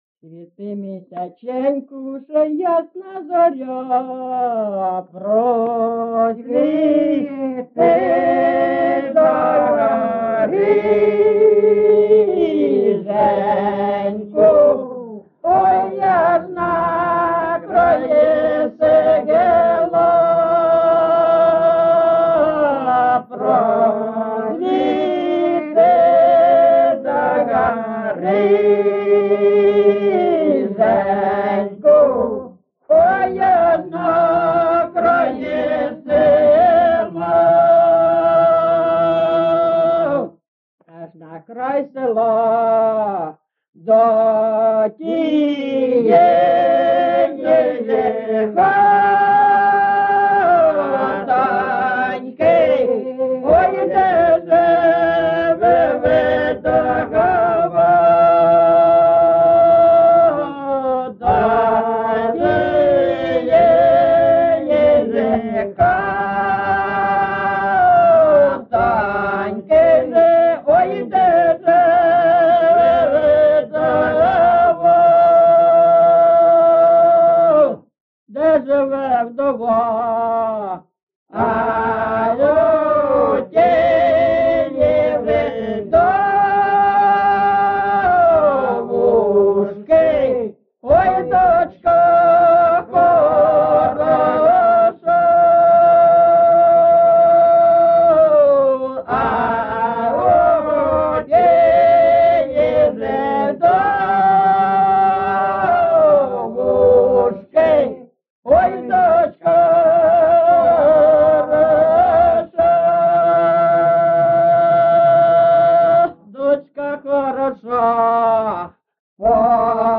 GenrePersonal and Family Life
Recording locationLyman, Zmiivskyi (Chuhuivskyi) District, Kharkiv obl., Ukraine, Sloboda Ukraine